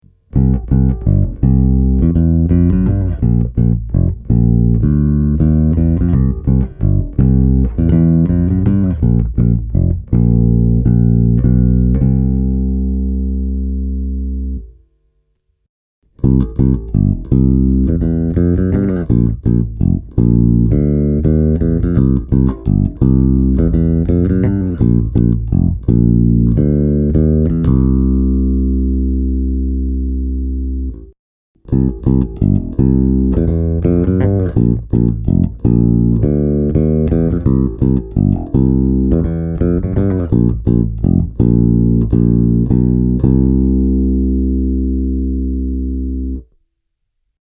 Atakki ja dynamiikka ovat hyvässä kunnossa, ja sointi on vapaa häiritsevistä sivuäänistä tai resonansseista.
Tässä kolme esimerkkisoundia (järjestyksessä: kaulamikki – molemmat – tallamikki):
Mannedesign Newport 4T – släpsoitto